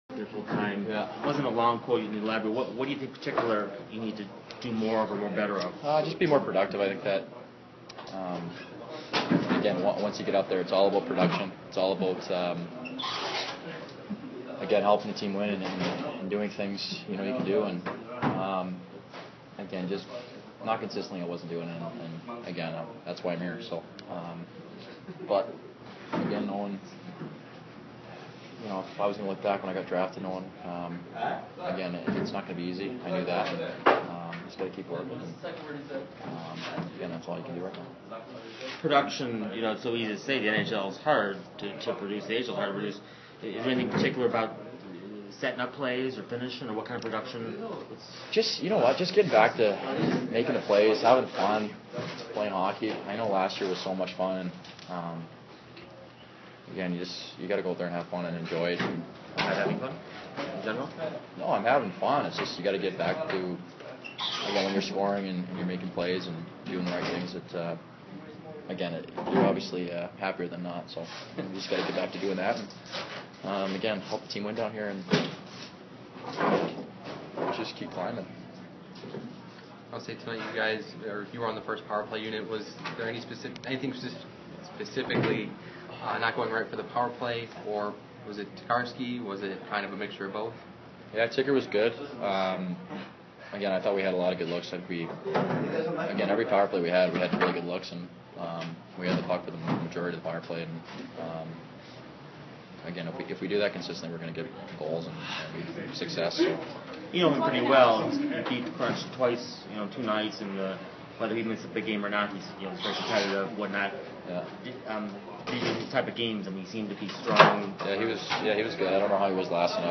November 23, 2013 Brett Connolly speaks to the press following a 3-0 loss to the Hamilton Bulldogs Download Listen Now Loading More Podcasts...
Connolly_Interview.mp3